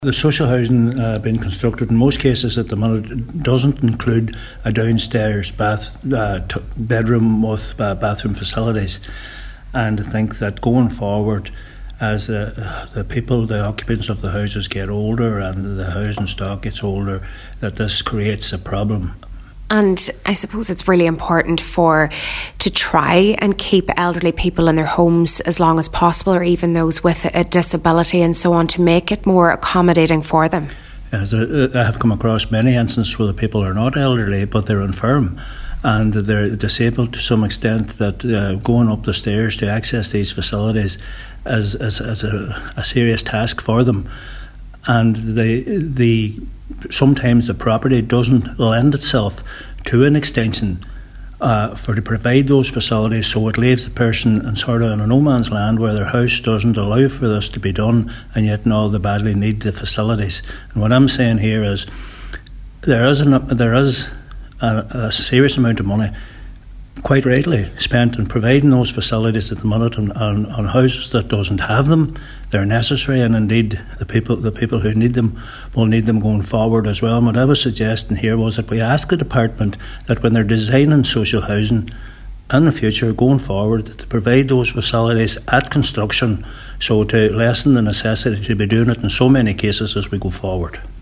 Cllr Gerry Crawford says he’s been in touch with a lot of people with a disability who are struggling living with inadequate facilities and believes a more proactive approach is needed: